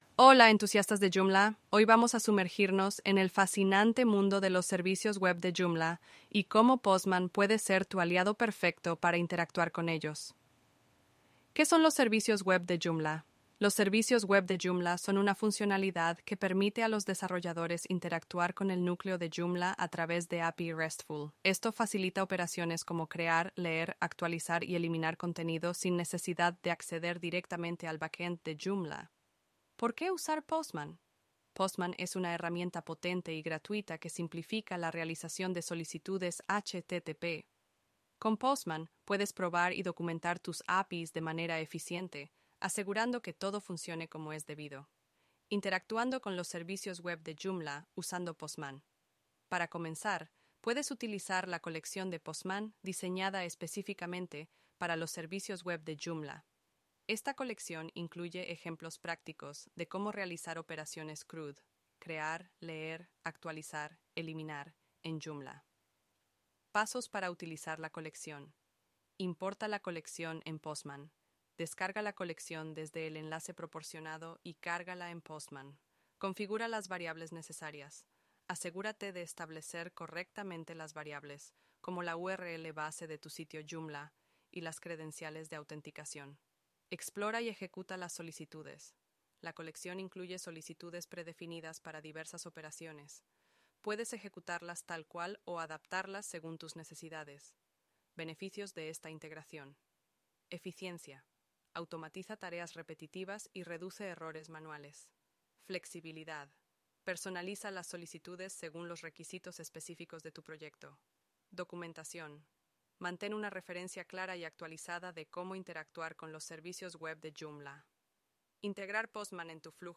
Podcast sobre Joomla en español con JoomlIA Robers, una IA